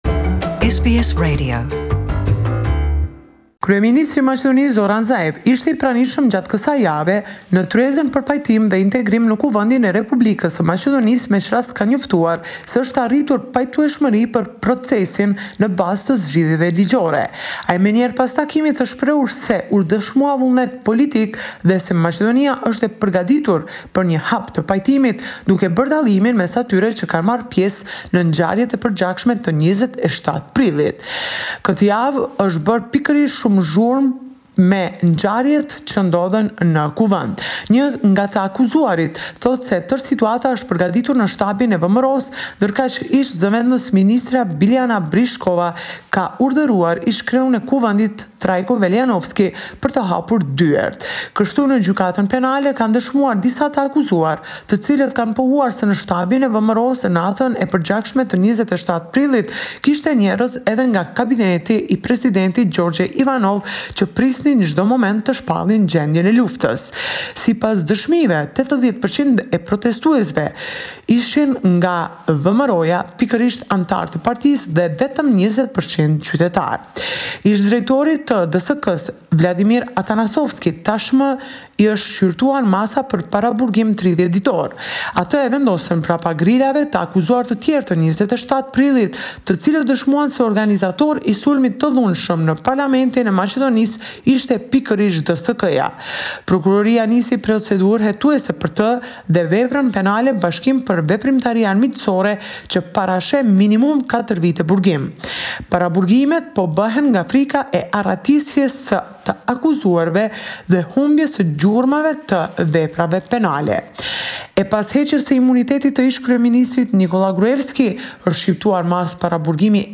This is a report summarising the latest developments in news and current affairs in Macedonia.